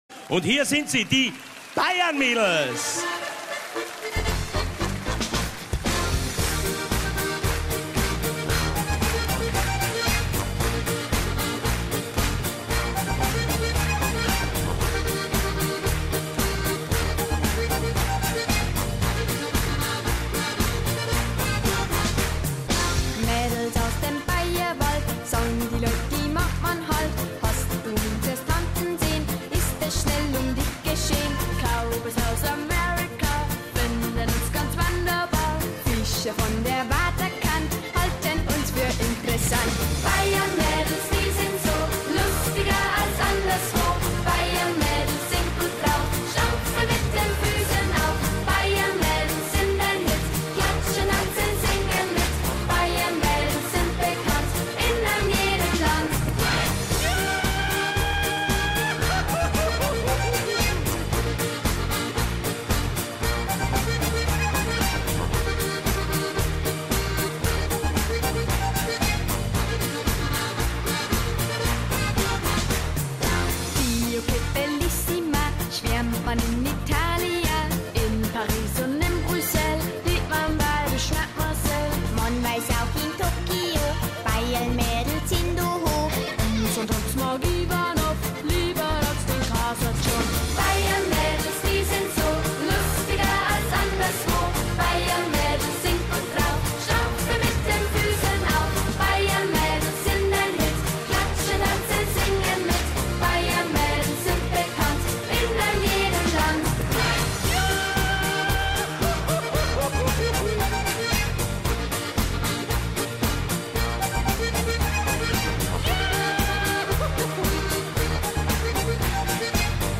2 Girls playing steirische harmonika on rollerskates